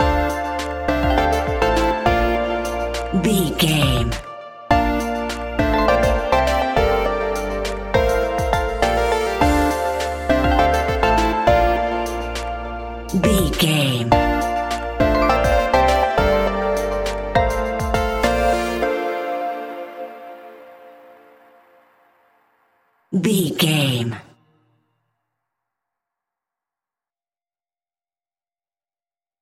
Aeolian/Minor
D
hip hop
instrumentals
chilled
laid back
hip hop drums
hip hop synths
piano
hip hop pads